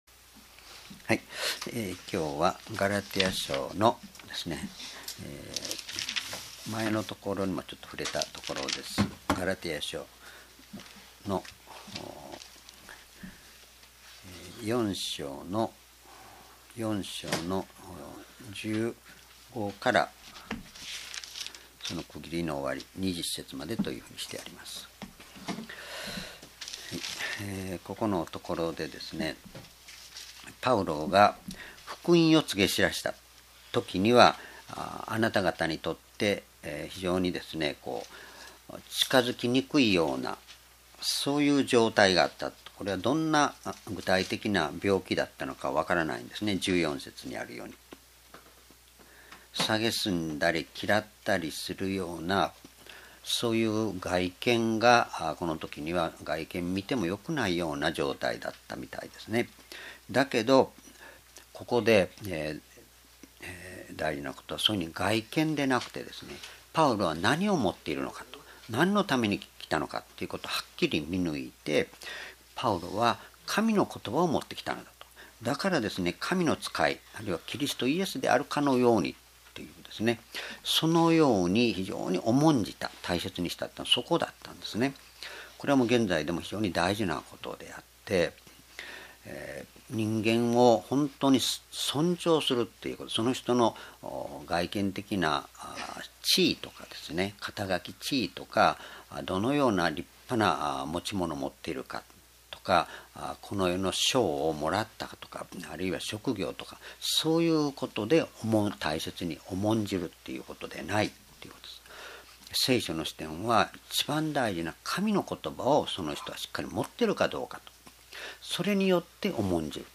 主日礼拝日時 ２０１４年１１月１６日 聖書講話箇所 ガラテヤの信徒への手紙 4章15-20 「キリストが形づくられるために」 ※視聴できない場合は をクリックしてください。